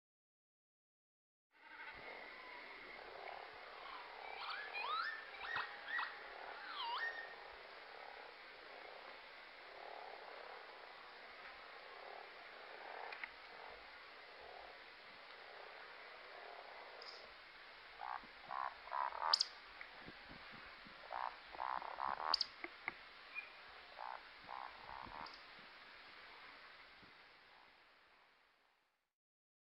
Kuuntele: Töyhtöhyyppä on kevätpellon akrobaatti